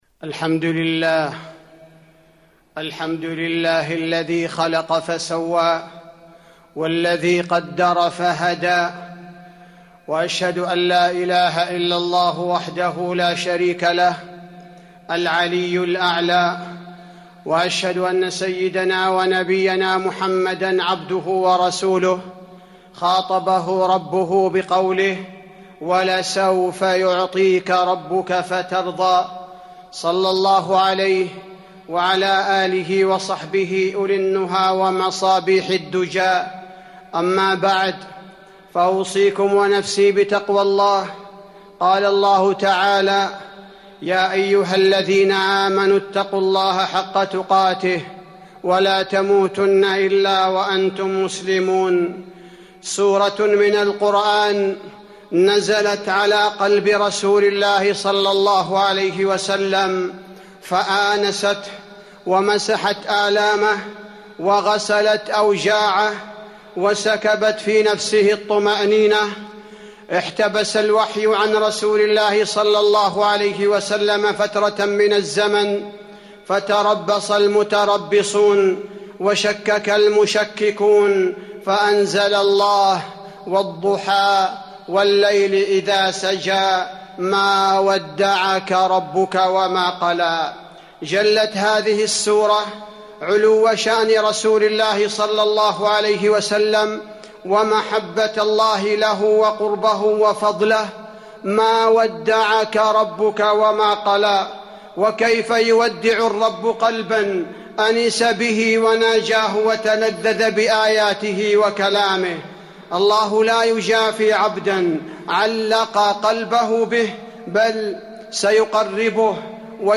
تاريخ النشر ٦ ربيع الأول ١٤٤٢ هـ المكان: المسجد النبوي الشيخ: فضيلة الشيخ عبدالباري الثبيتي فضيلة الشيخ عبدالباري الثبيتي تأملات في هدايات سورة الضحى